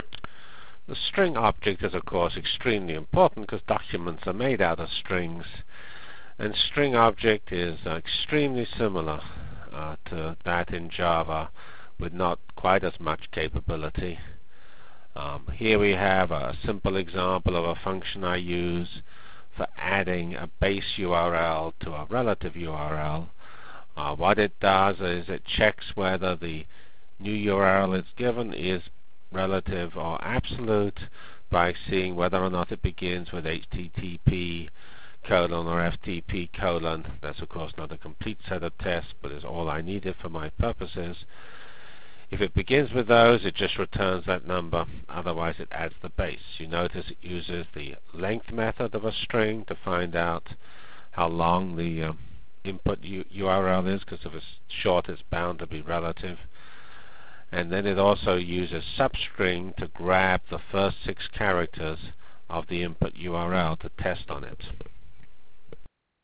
Delivered Lecture